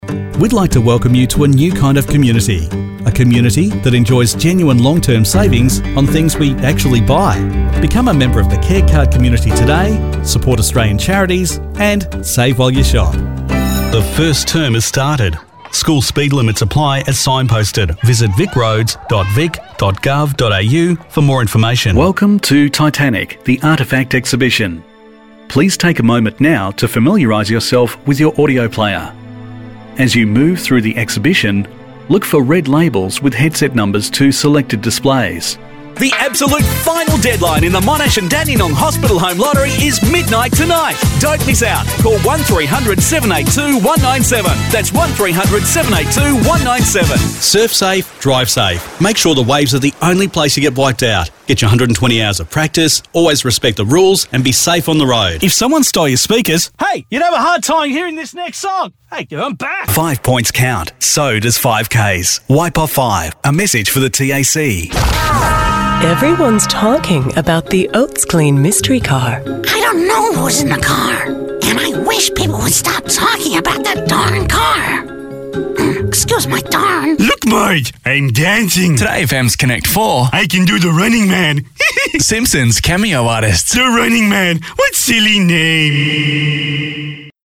Engaging, articulate, and emotive male voice, slightly deep register that can go full "Kevin Conroy" if desired.